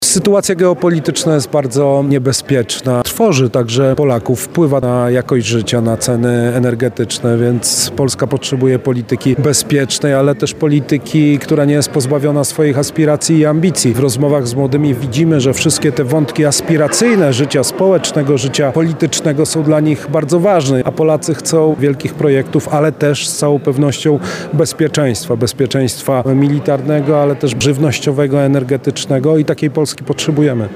Popierany przez PiS kandydat na prezydenta Karol Nawrocki spotkał się w sobotę (07.12) z mieszkańcami Lublina. Podczas rozmów mówił między innymi o kwestiach związanych z ekshumacjami ofiar zbrodni wołyńskiej, a także o tym jak i czym może przekonać do siebie wyborców.
Spotkanie z Karolem Nawrockim odbyło się w Filharmonii Lubelskiej im. Henryka Wieniawskiego przy ul. Marii Curie-Skłodowskiej w Lublinie.